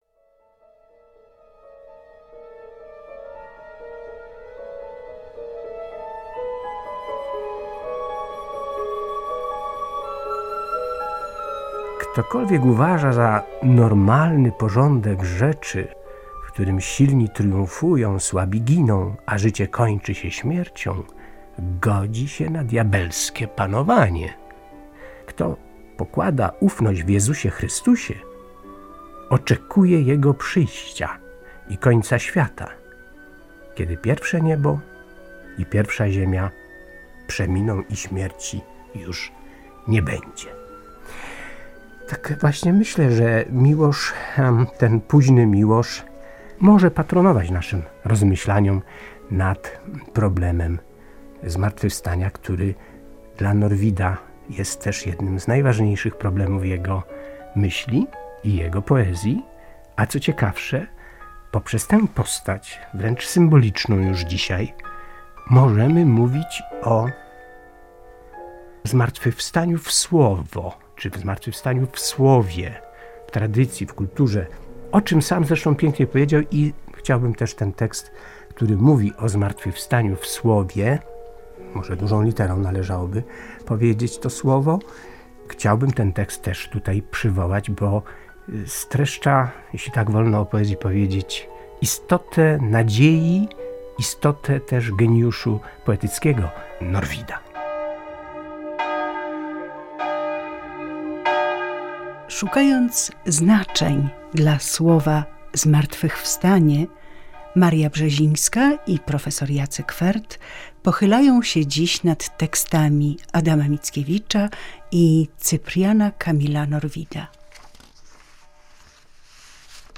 Literacka audycja artystyczna.